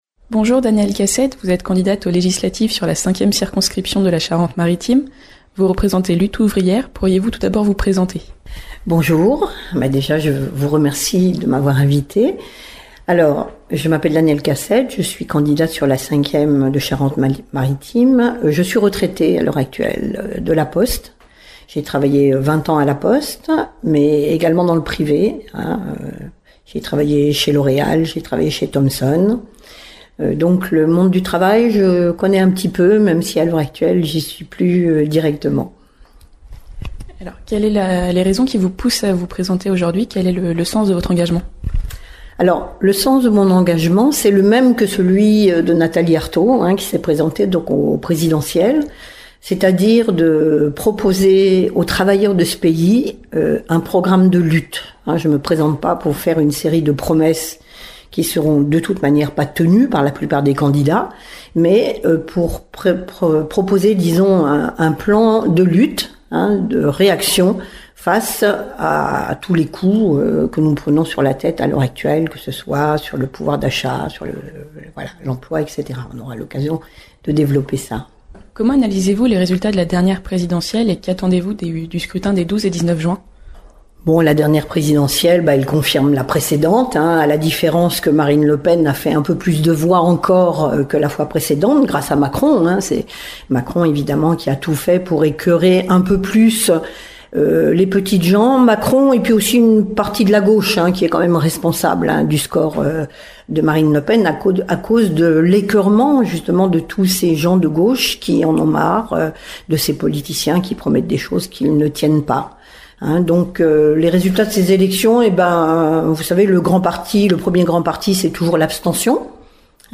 On poursuit notre série d’entretiens avec les candidats aux Législatives en Charente-Maritime.